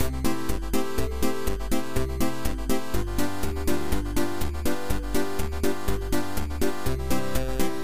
Music Loops
Retro Polka.ogg